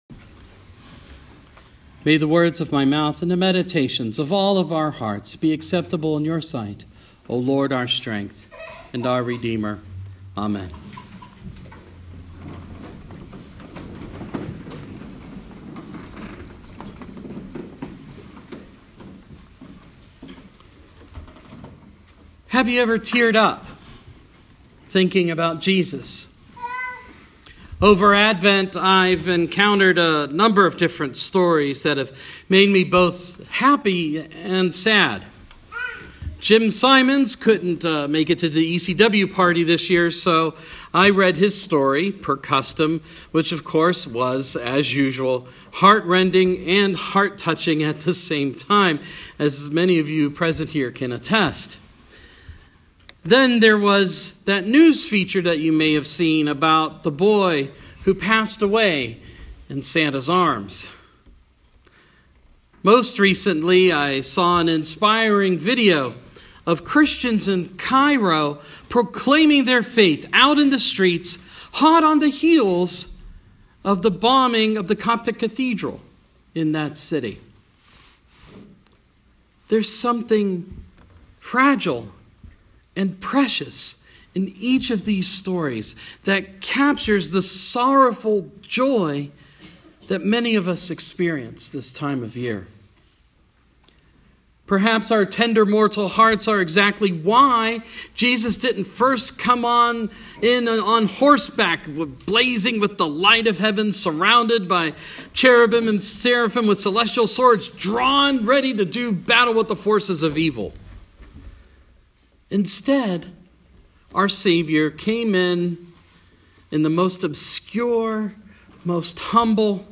Christmas Eve